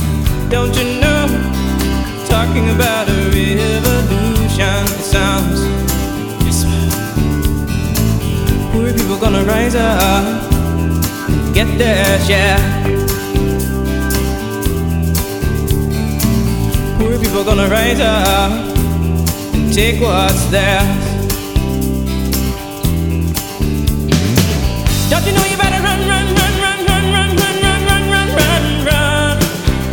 • Singer/Songwriter